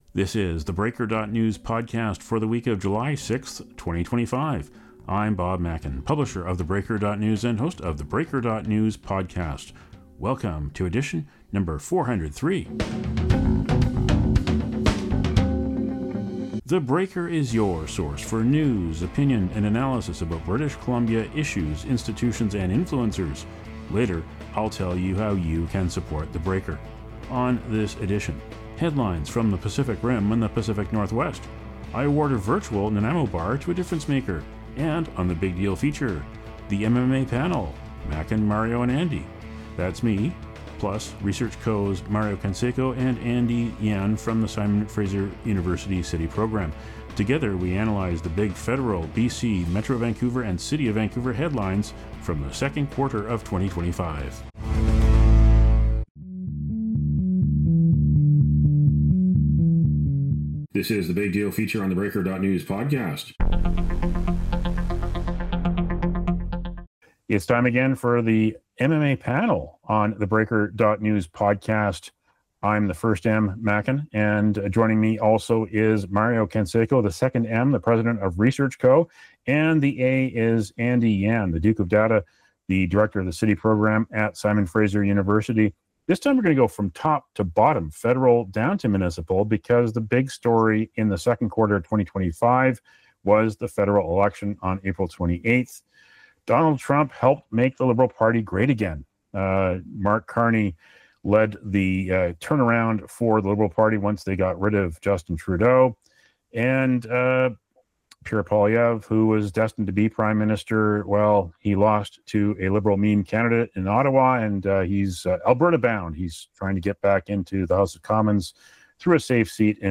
For the week of July 6, 2025: The MMA Panel returns to analyze the second quarter of 2025.